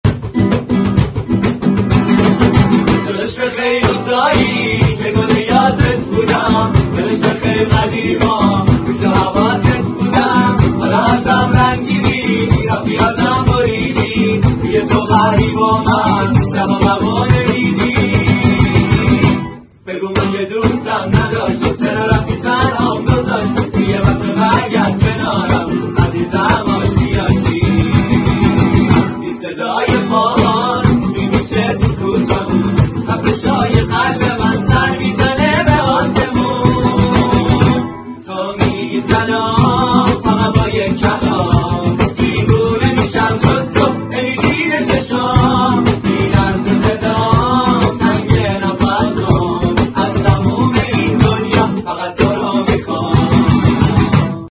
اجرای گروهی با گیتار